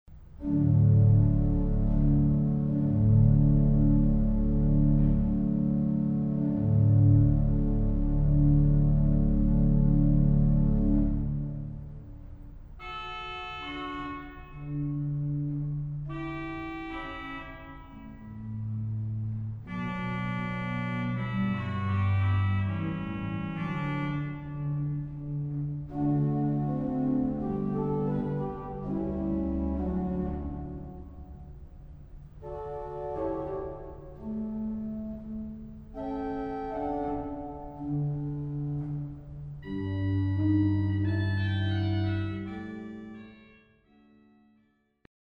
Récit de Hautbois accompagné par les Flûtes du Positif et de la Pédale ; le Grand orgue fait les « tutti » et dialogue parfois avec le Hautbois 1 ; quelques interventions de la Trompette d’Echo pour les pp de la partie récitante.
Positif : Montre 8, Bourdon 8, 1ère et 2e Flûte 8
G.O. : Bourdon-Flûte 8, Flûte 8
Récit : Hautbois
Echo : Trompette
Pédale : Soubasse 16, Flûte 8, Flûte 4